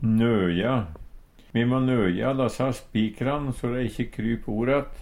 nøja - Numedalsmål (en-US)
Høyr på uttala Ordklasse: Verb Kategori: Handverk (metall, tre, lær) Attende til søk